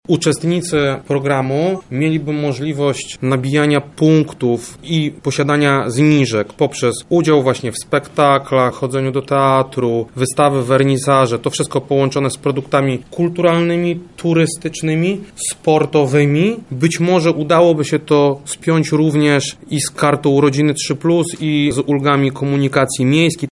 – wylicza zastępca prezydenta Lublina Krzysztof Komorski.